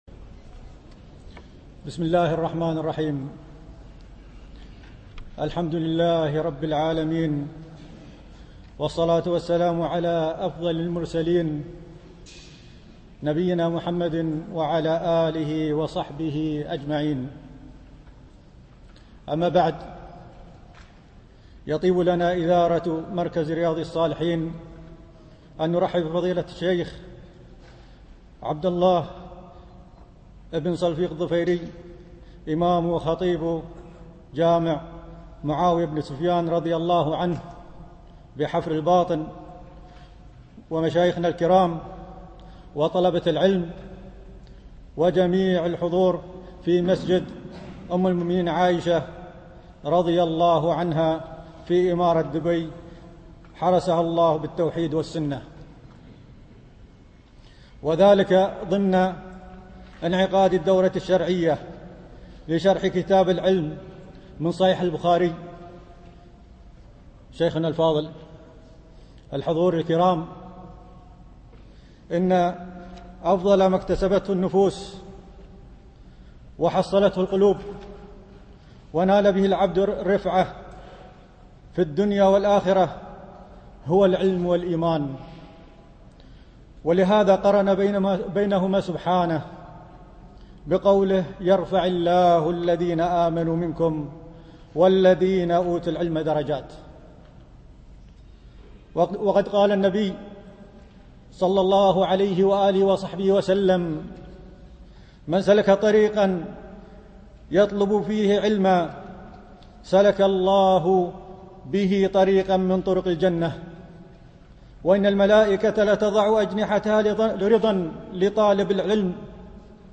دروس مسجد عائشة (برعاية مركز رياض الصالحين ـ بدبي)